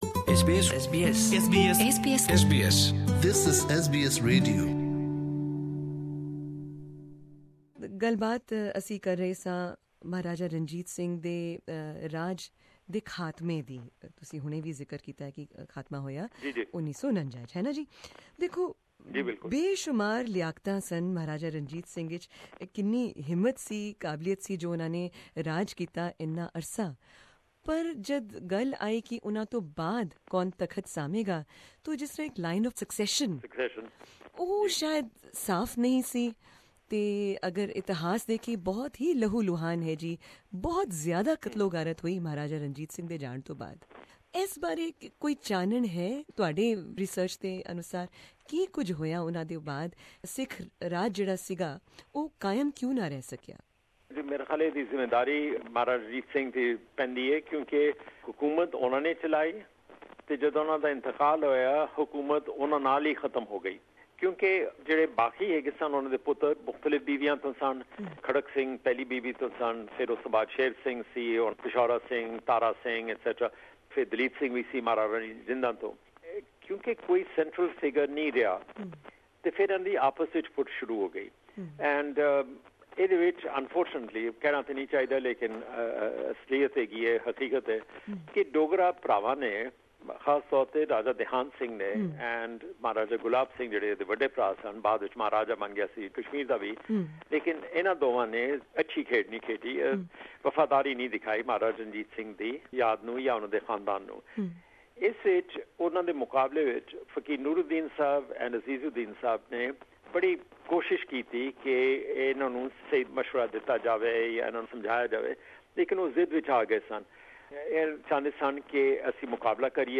In this, the conclusion of our extensive interview with Lahore based writer and historian Fakir Aijazuddin, we pose a few questions: 1.